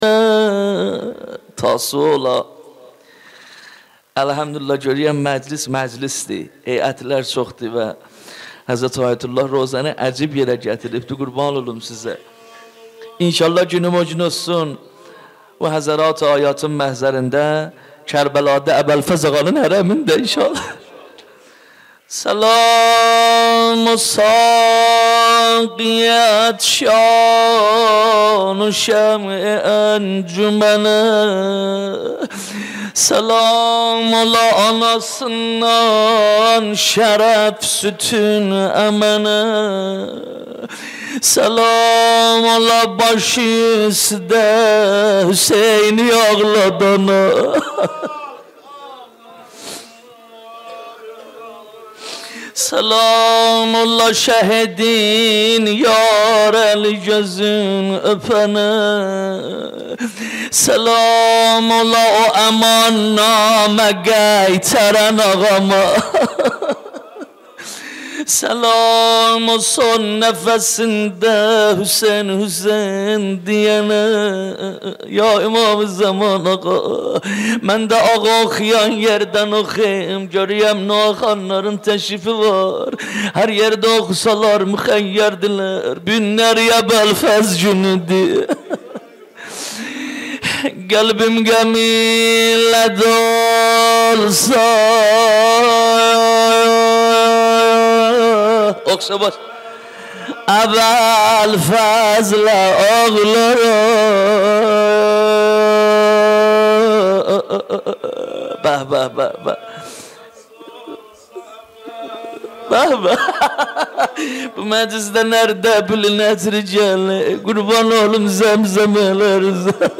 مراسم تاسوعای حسینی
نوحه جديد
مداحی صوتی